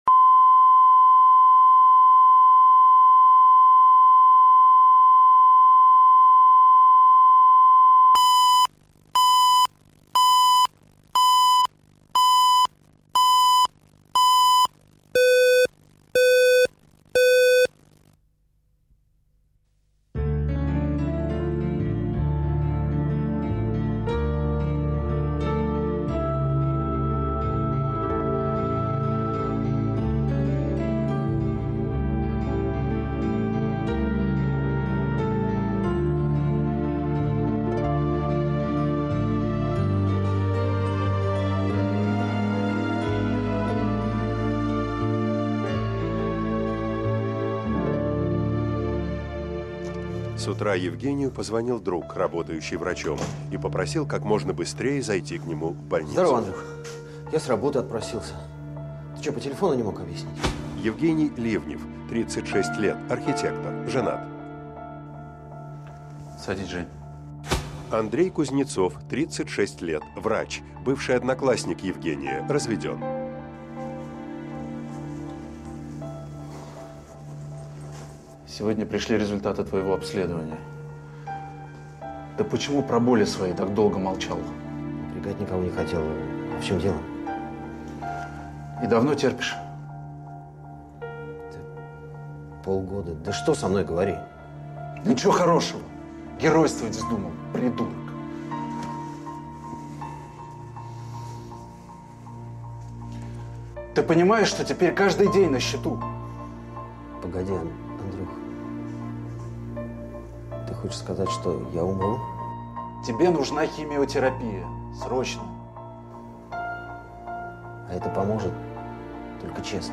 Аудиокнига Пока я жив | Библиотека аудиокниг